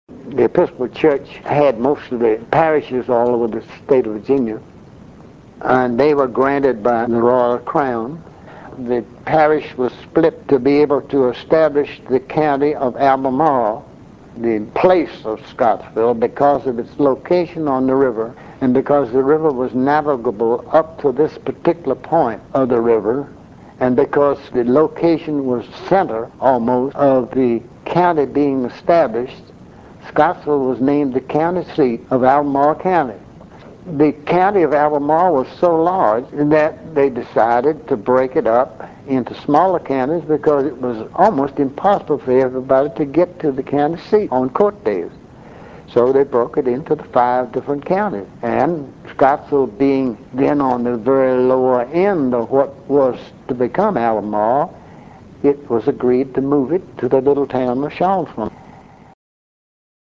Oral History - Government